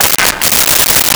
Camera Auto Click 01
Camera Auto Click 01.wav